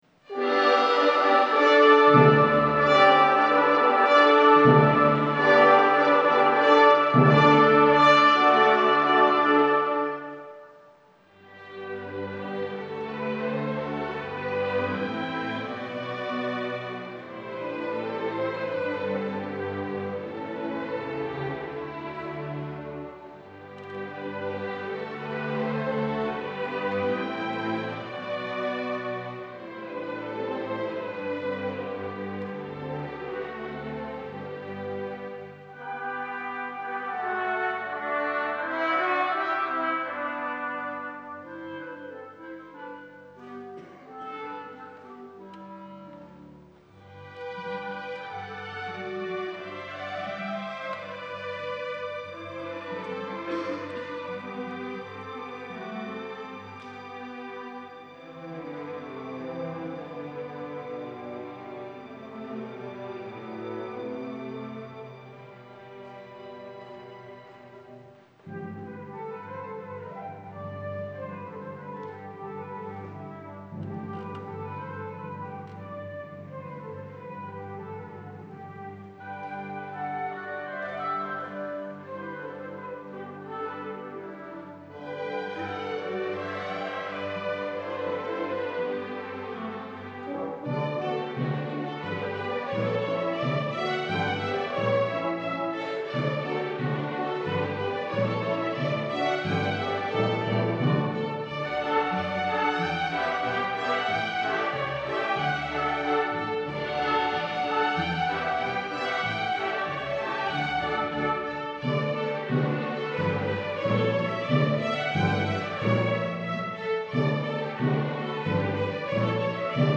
Encore: An English Overture - Fantasy on 3 Traditional Reels (performed November 2017) Kingston Chamber Orchestra